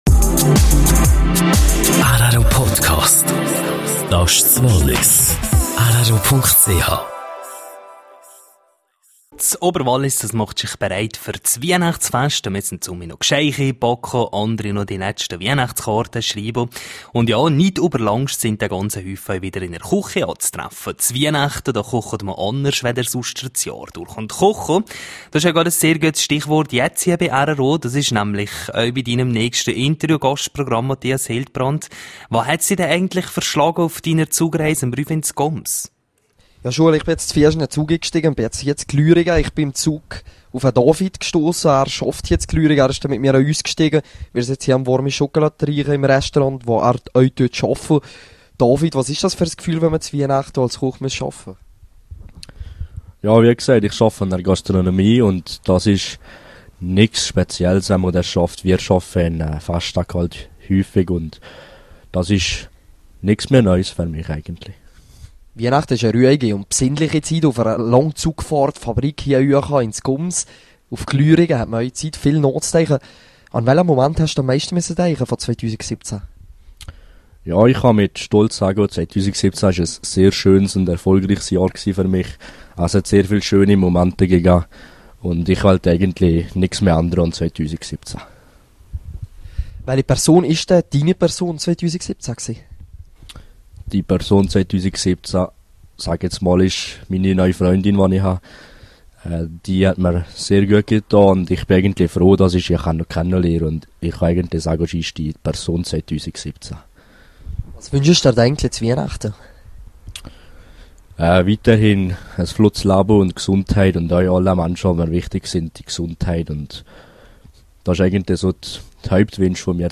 rro unterwegs im Zug: Interview